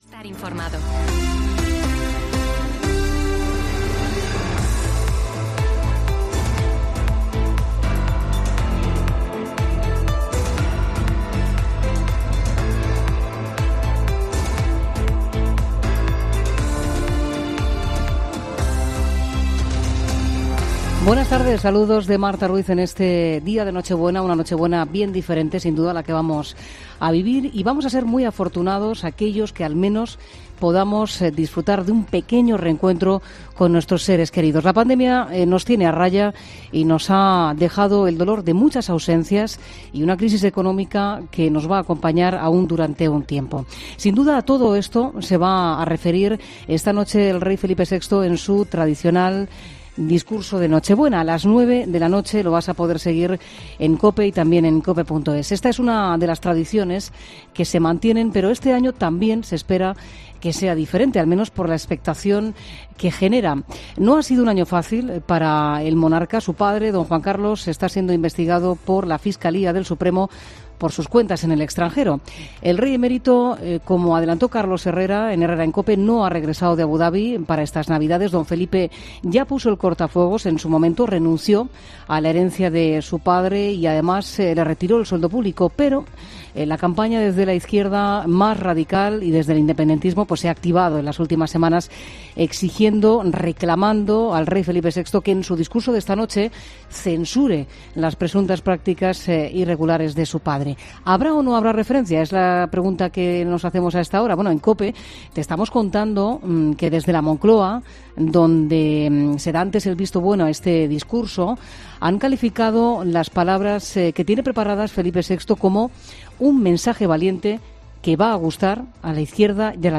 Monólogo